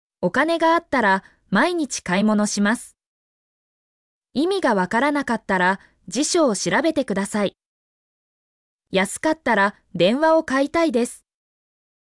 mp3-output-ttsfreedotcom-77_OQE9ORr9.mp3